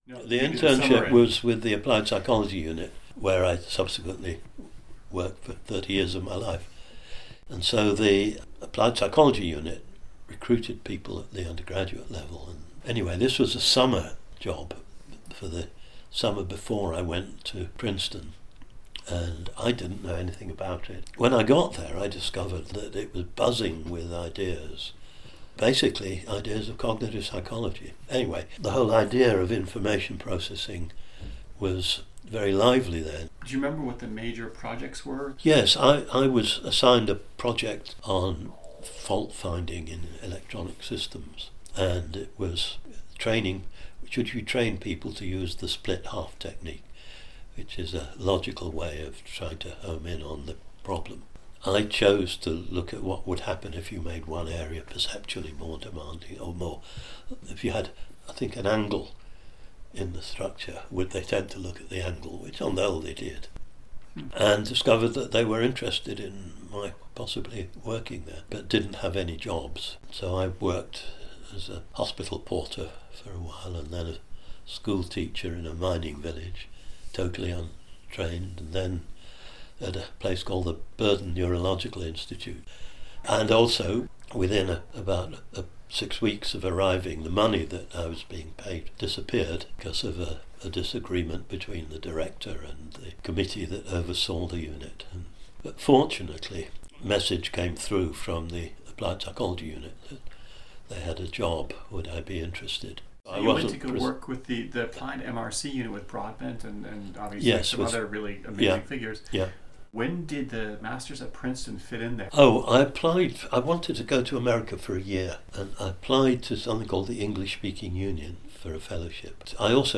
I am thrilled to share with you some excerpts and ideas from my lively conversation with the one of Psychology’s bad asses, Alan Baddeley!